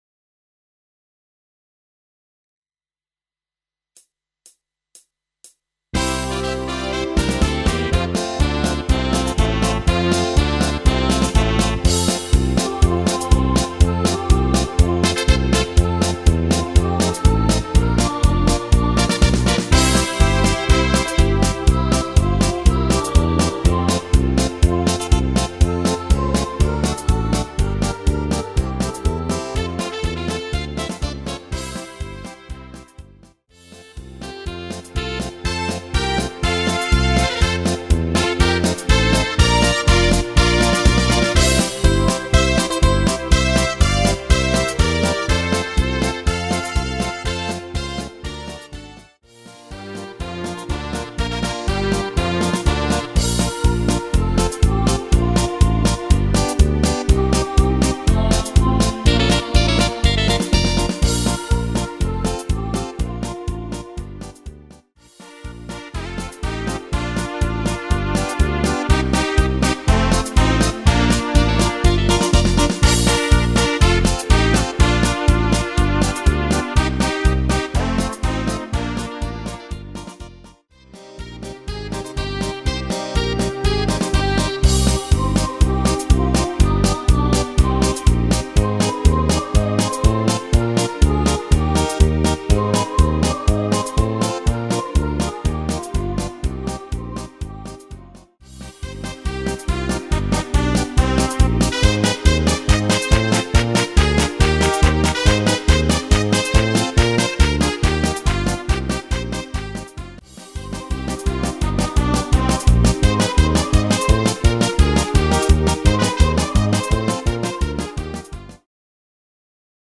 Moravská lidová
Rubrika: Národní, lidové, dechovka
- směs - polka